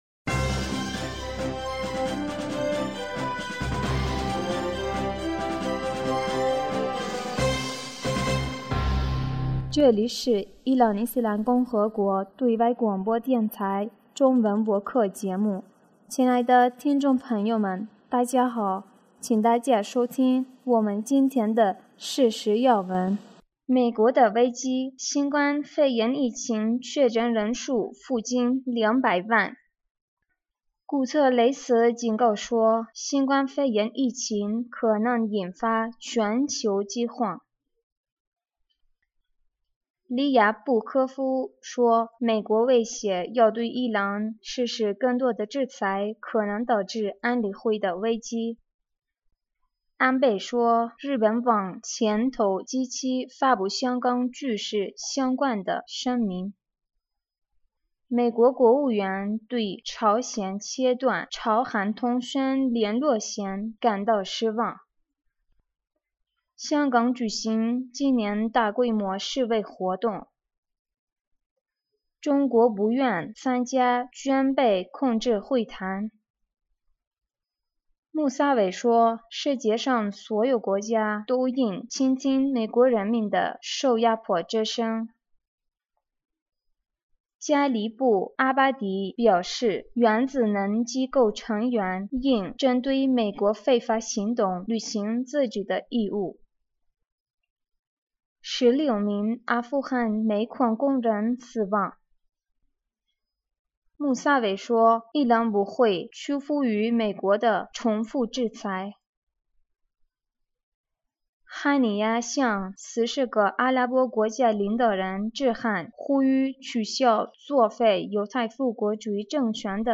2020年6月10日 新闻